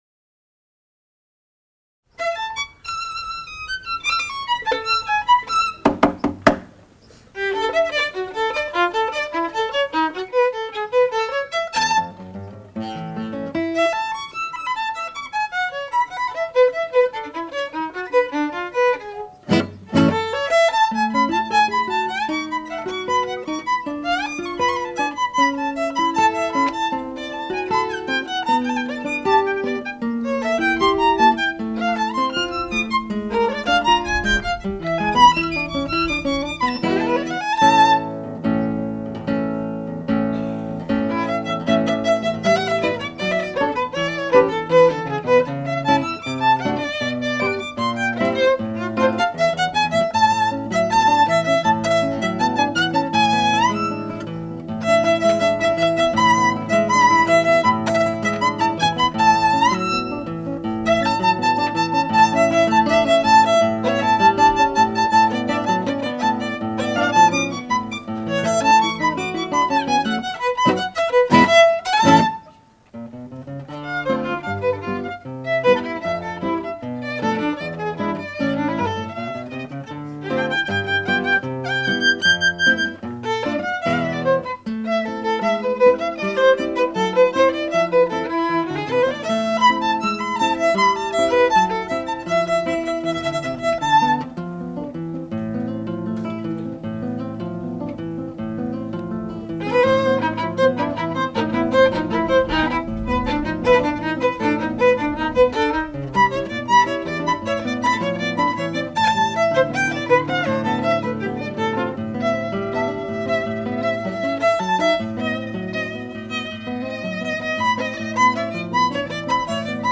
in Belmonte Castle